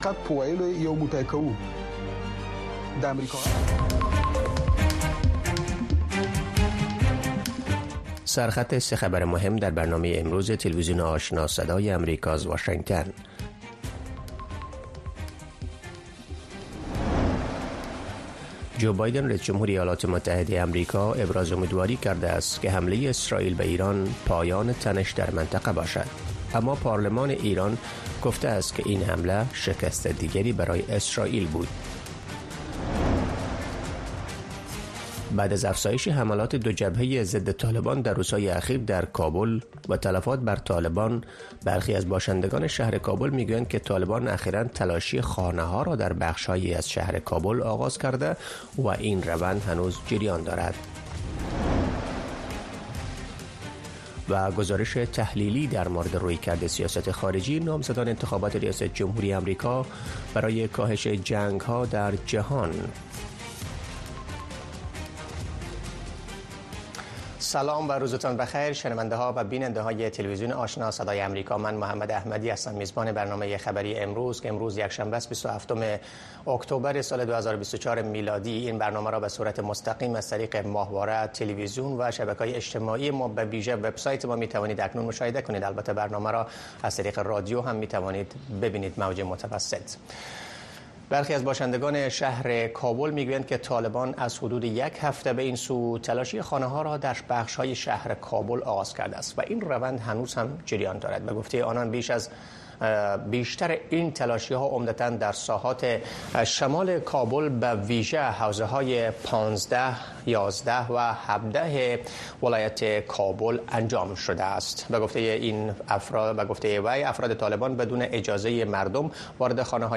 تازه‌ترین خبرهای افغانستان، منطقه و جهان، گزارش‌های جالب و معلوماتی از سراسر جهان، مصاحبه‌های مسوولان و صاحب‌نظران، صدای شما و سایر مطالب را در برنامهٔ خبری آشنا از روزهای شنبه تا پنج‌شنبه در رادیو، ماهواره و شبکه های دیجیتلی صدای امریکا دنبال کنید.